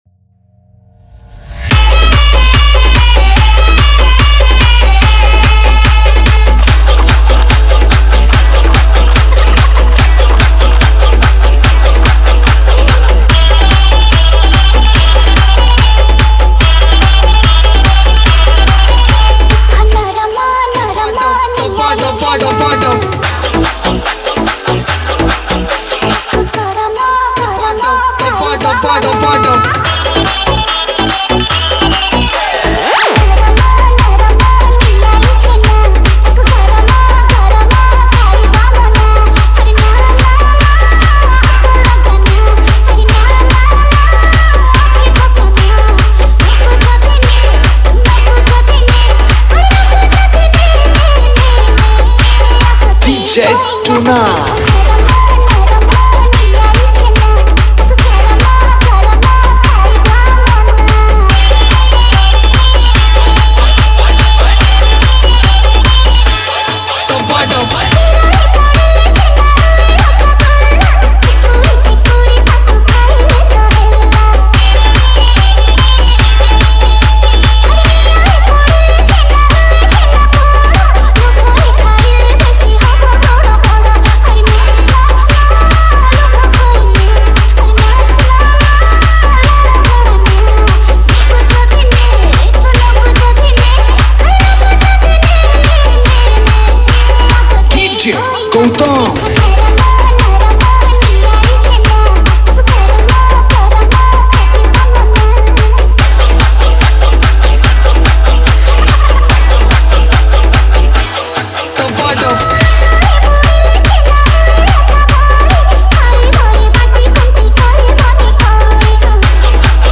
Category:  New Odia Dj Song 2022